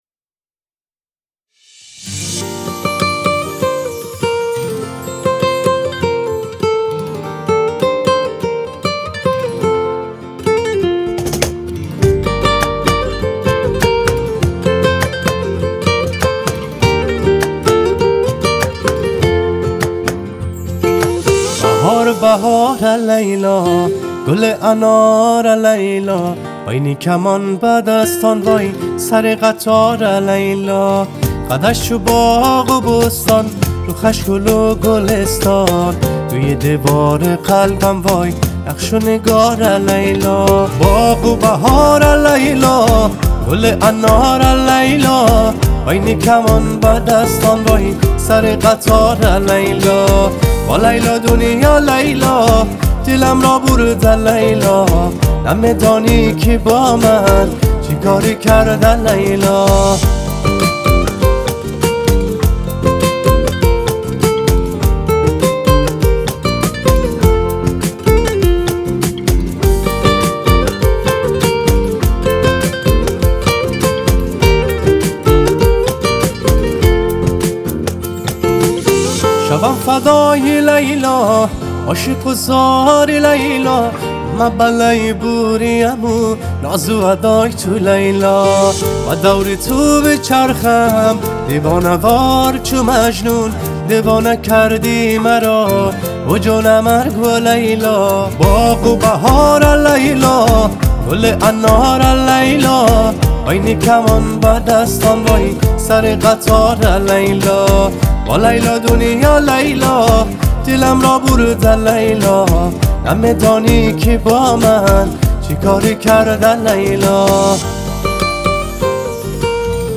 آهنگ افغانی
خیلی قشنگ میخونه